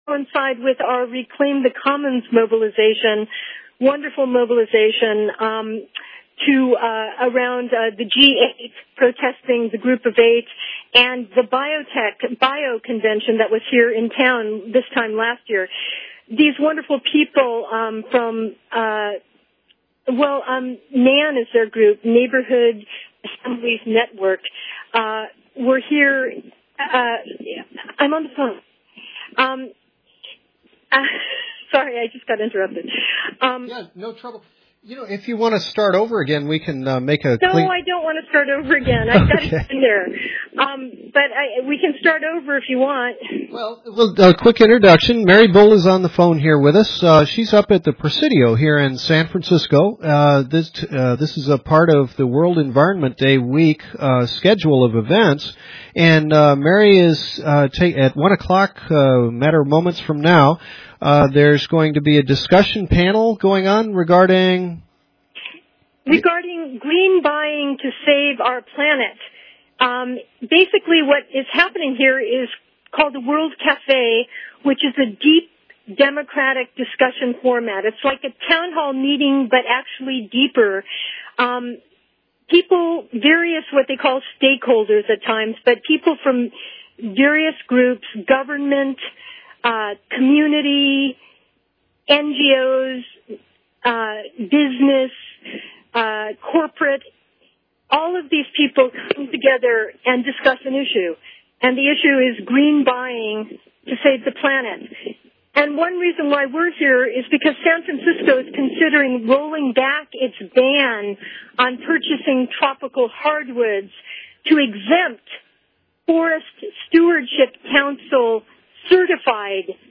World Environment Day panel on sustainable purchasing for homes, institutions and governments.